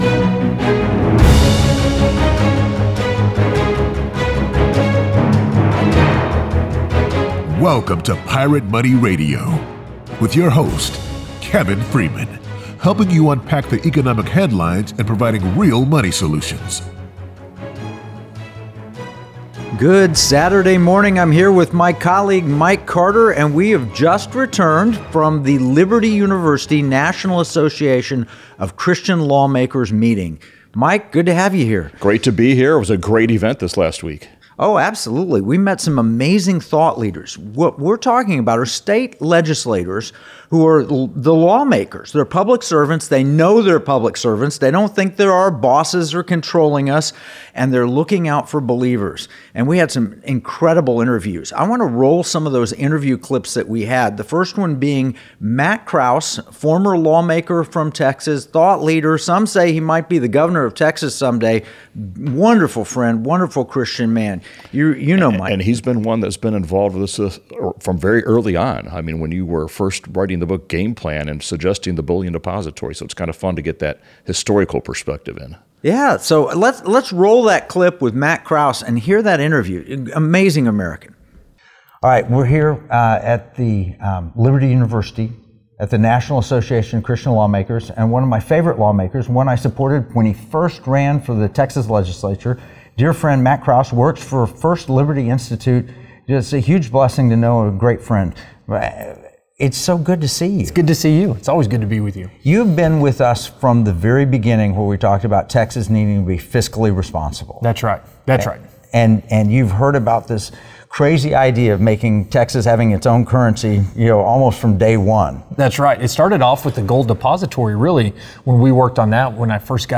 Hear illuminating interviews with state legislators discussing the transformative potential of transactional gold and silver. Discover how states like Texas, Florida, and Alaska are pioneering gold-backed solutions to safeguard economic liberty.